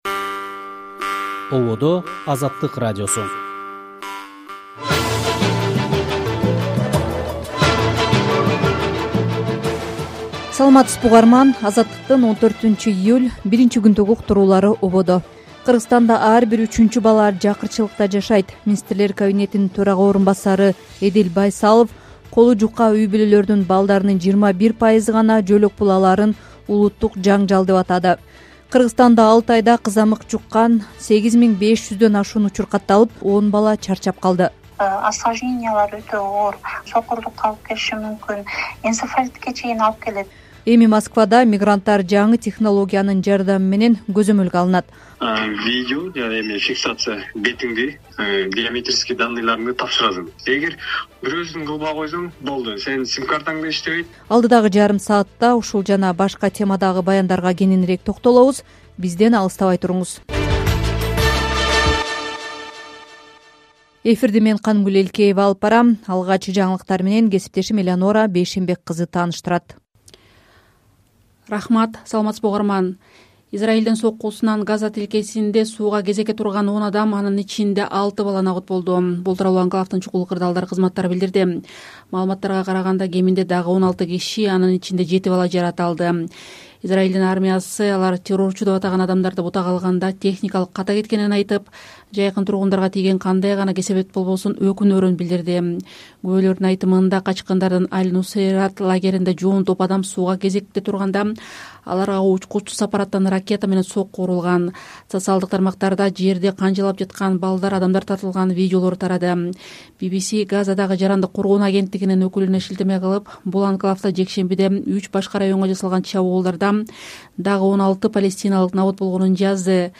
Азаттык: Кечки радио эфир | 14.07.2025 | Жыл башынан бери кызамык жуккан 10 бала чарчап калды